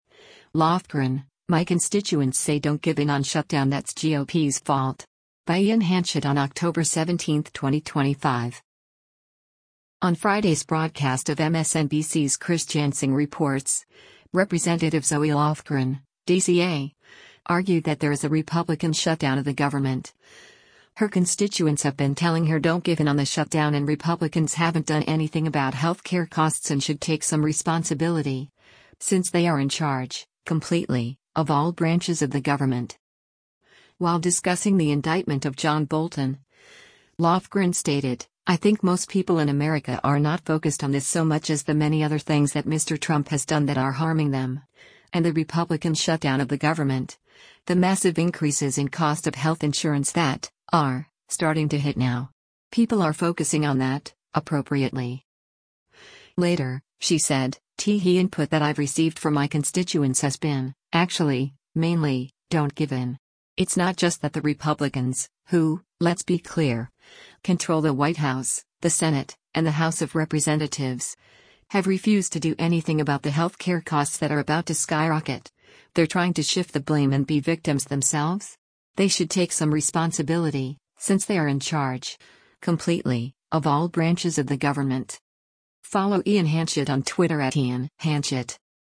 On Friday’s broadcast of MSNBC’s “Chris Jansing Reports,” Rep. Zoe Lofgren (D-CA) argued that there is a “Republican shutdown of the government,” her constituents have been telling her “don’t give in” on the shutdown and Republicans haven’t done anything about healthcare costs and should “take some responsibility, since they are in charge, completely, of all branches of the government.”